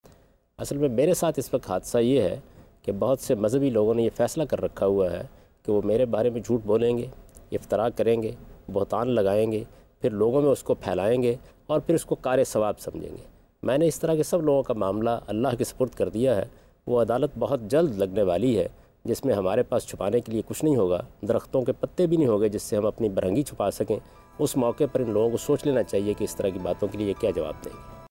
Category: TV Programs / Geo Tv / Ghamidi /
Javed Ahmad Ghamidi expresses his thoughts about baseless allegations on him.